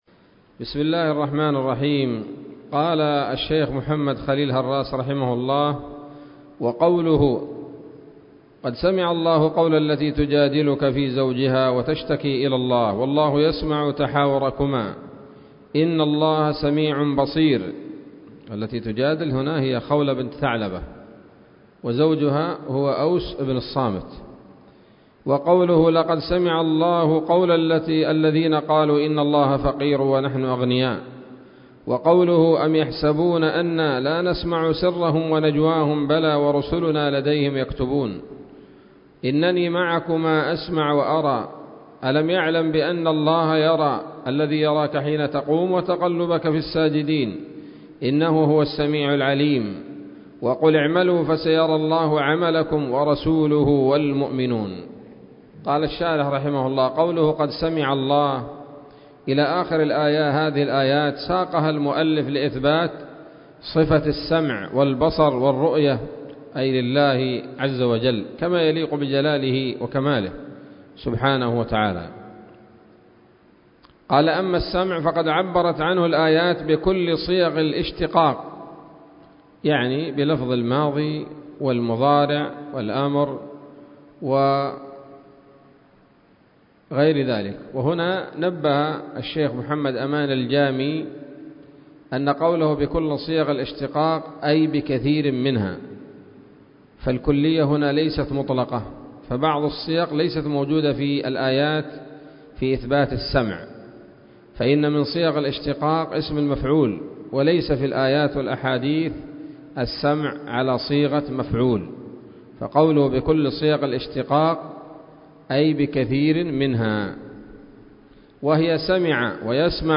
الدرس الخامس والستون من شرح العقيدة الواسطية للهراس